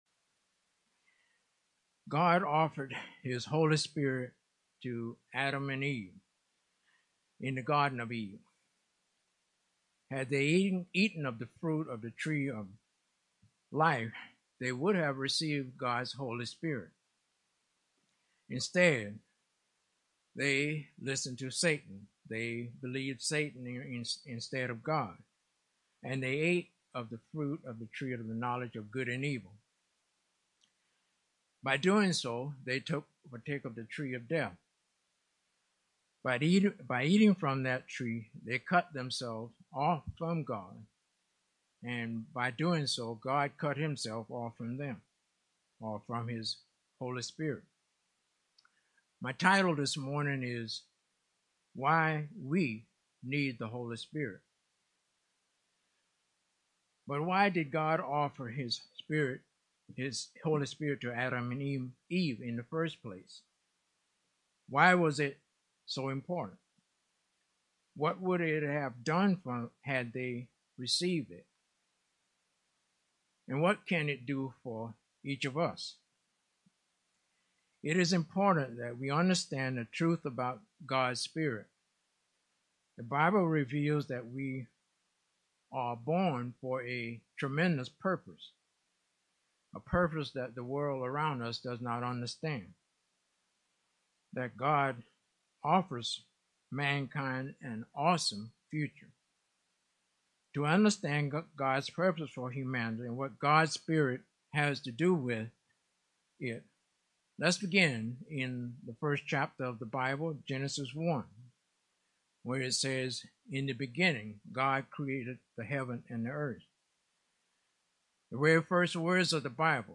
Sermons
Given in St. Petersburg, FL Tampa, FL